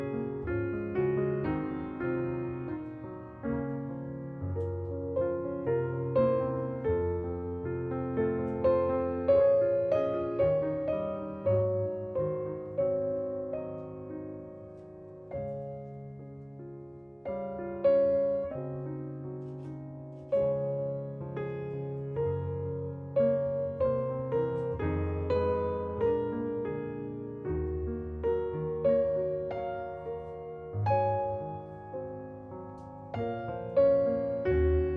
Жанр: Джаз